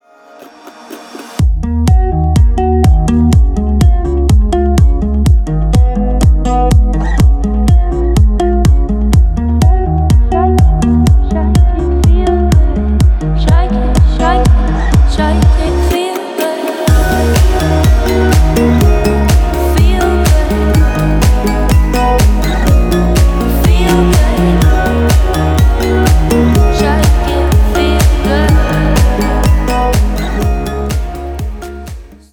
• Качество: 320, Stereo
гитара
deep house
спокойные
расслабляющие
relax